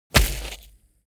playerGroundHit4.wav